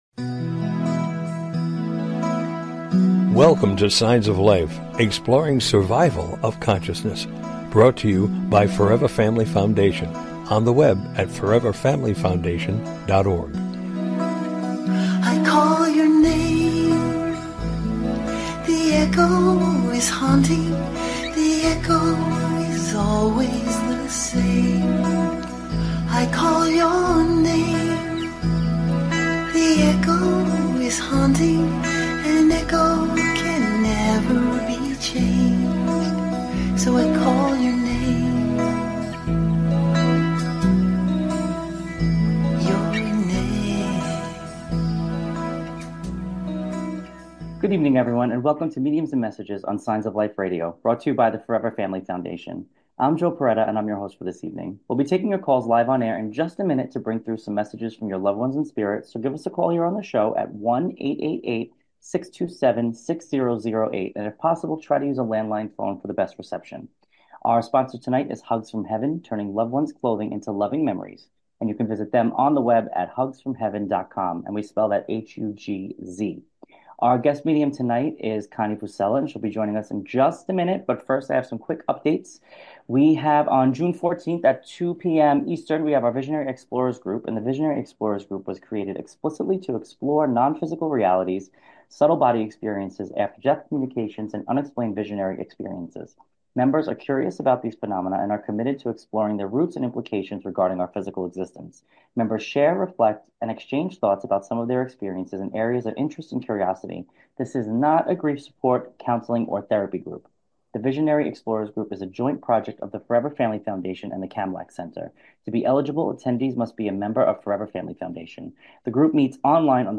Signs of Life Radio Show is a unique radio show dedicated to the exploration of Life After Death! Call In or just listen to top Scientists, Mediums, and Researchers discuss their personal work in the field and answer your most perplexing questions. Topics will include: Mediumship, Near Death Experiences, Death Bed Visions, Reincarnation, Apparitions and Poltergeists, After Death Communication, ESP and Telepathy, Survival of Consciousness ..... and the list is endless!